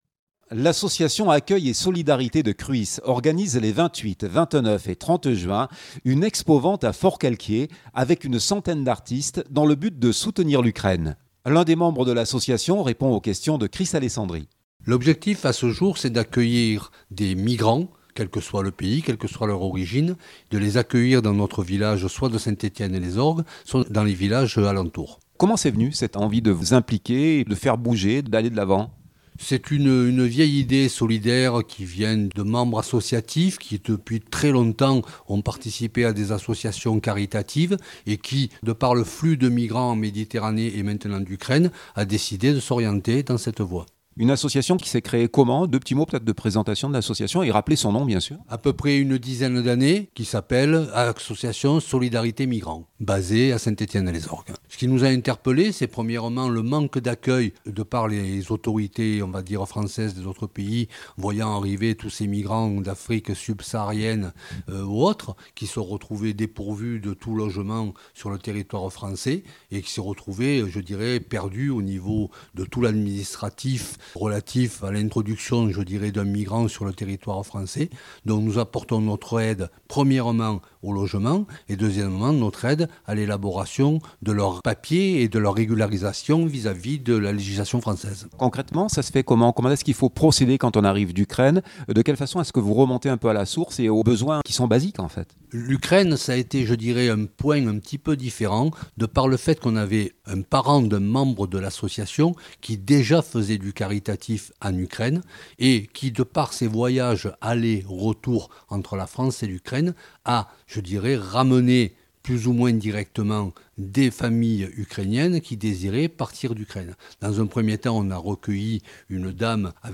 l’association « Accueil et solidarité » de Cruis, organise les 28, 29 et 30 juin, une Expo-Vente à Forcalquier avec une centaine d’artistes dans le but de soutenir l’Ukraine. L’un des membres de l’association répond aux questions